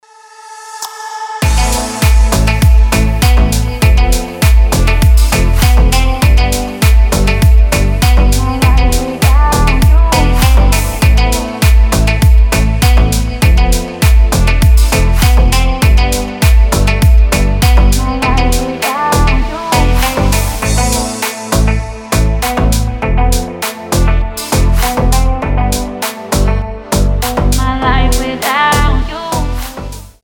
• Качество: 320, Stereo
женский голос
красивая мелодия
Жанр: Deep house